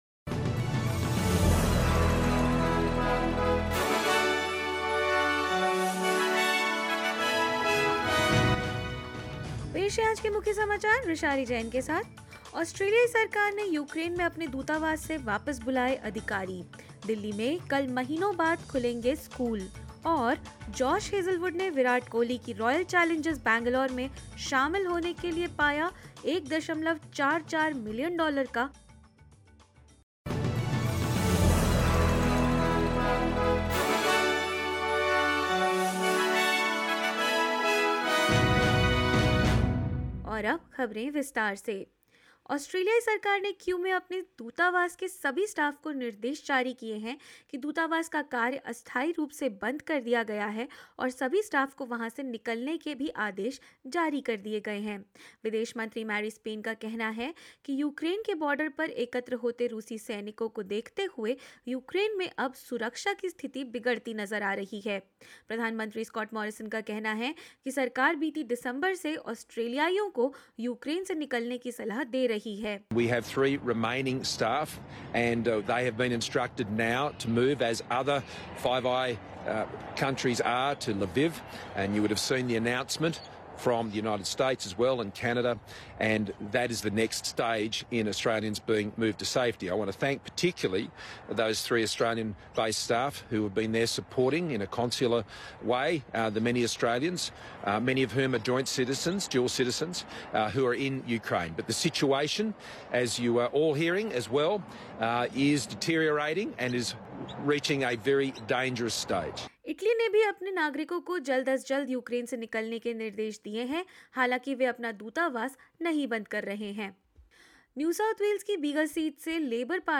In this latest SBS Hindi bulletin: Australia shuts its Ukraine embassy amidst threats of Russian aggression; Students to return to classrooms tomorrow after months in Delhi; Josh Hazlewood secures a $1.44 million deal to join Virat Kohli's Royal Challengers Bangalore and more news.